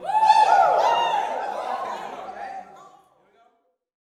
WOO  01.wav